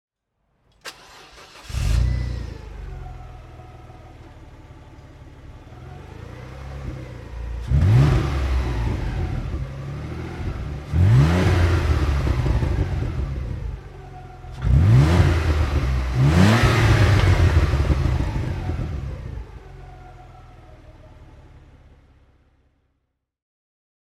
Renault Sport Clio V6 24V (2002) - Starten und Leerlauf
Renault_Sport_Clio_V6_2003.mp3